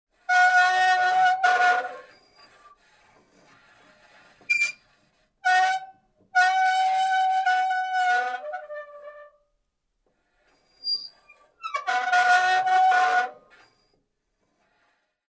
Звук скрипящей карусели